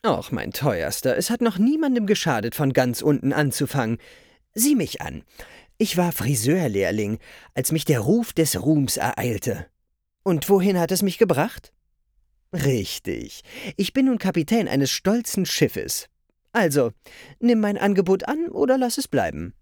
Sprachbeispiel des Captain Narrow